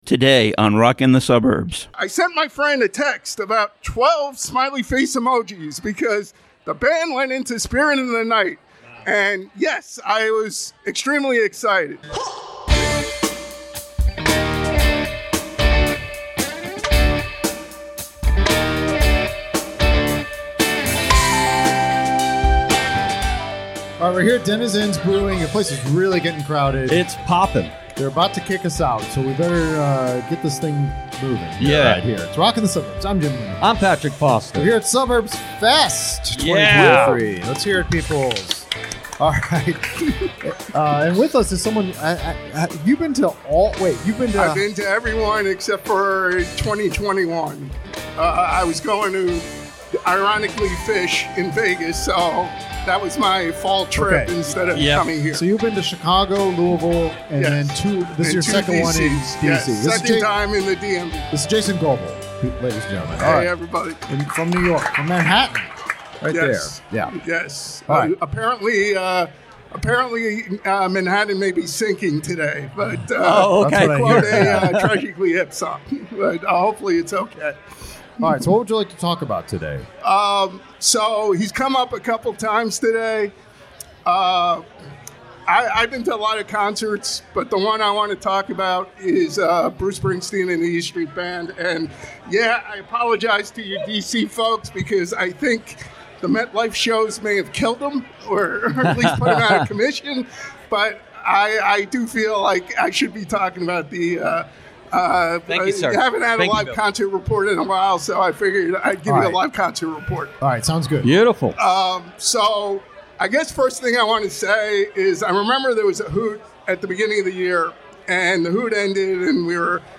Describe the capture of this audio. We have a few more episodes recorded live at Denizens Brewing Company during Suburbs Fest.